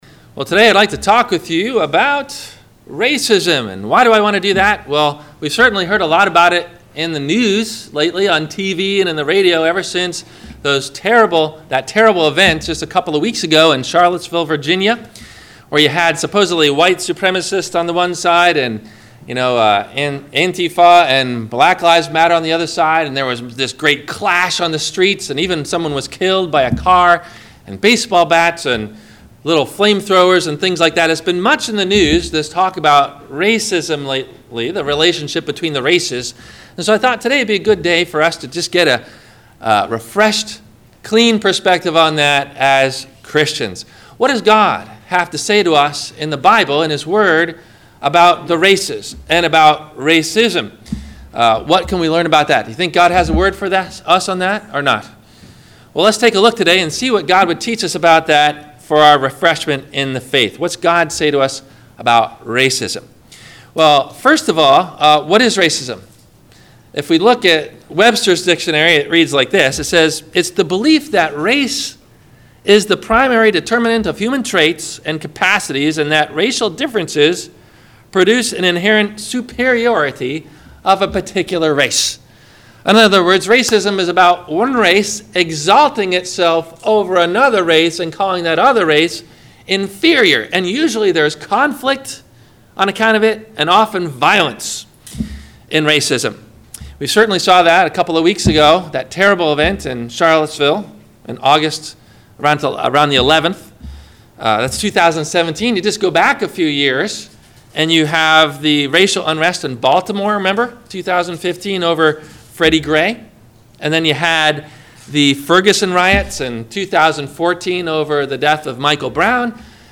The Secret to a Content Heart – WMIE Radio Sermon – October 09 2017